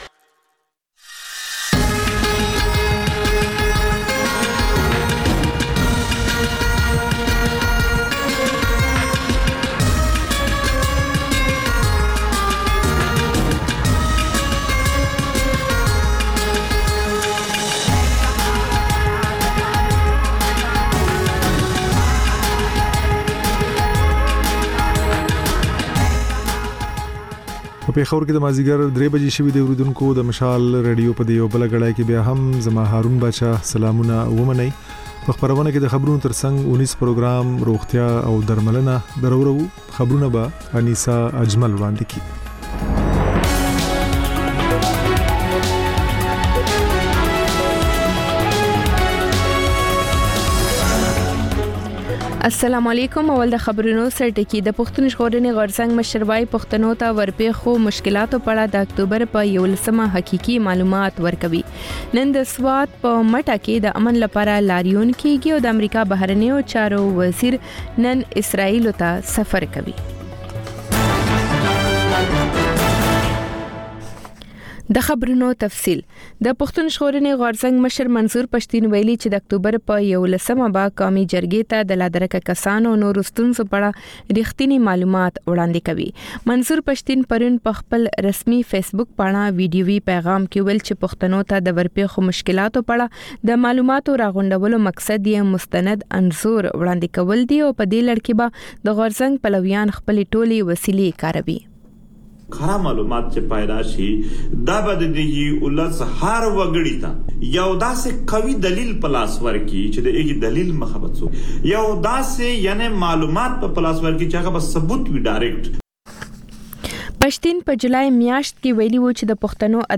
د مشال راډیو درېیمه یو ساعته ماسپښینۍ خپرونه. تر خبرونو وروسته، رپورټونه او شننې خپرېږي.